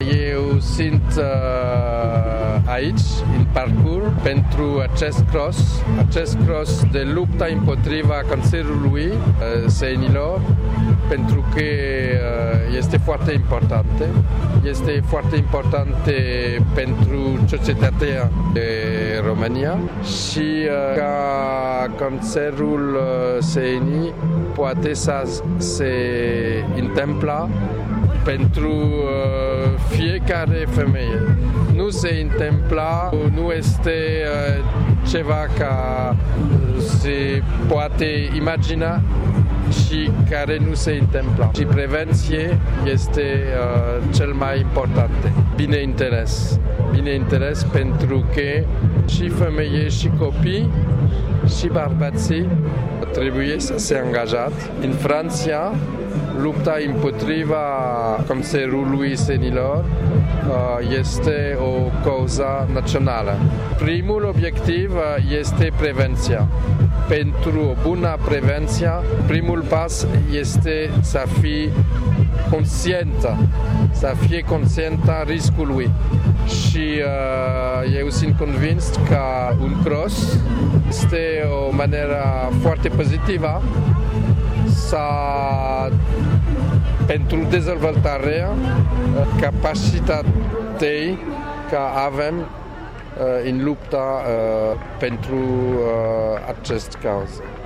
La eveniment a fost prezent şi ambasadorul Franţei, Francois Saint-Paul, care a precizat pentru București FM că lupta împotriva cancerului la sân este foarte importantă, adăugând că, atunci când este depistat şi tratat la timp, acesta poate fi învins.